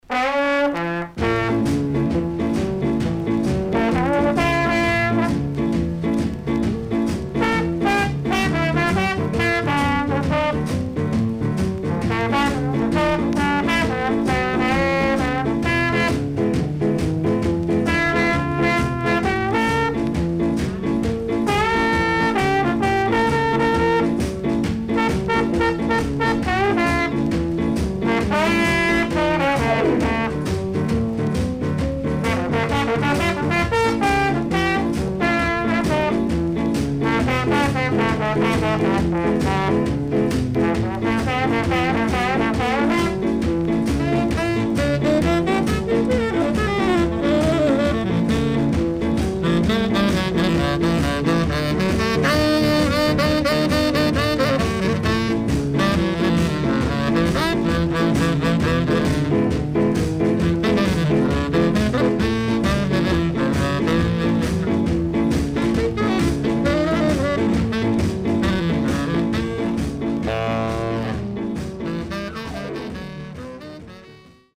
CONDITION SIDE A:VG〜VG(OK)
SIDE A:プレス起因によるチリノイズ入ります。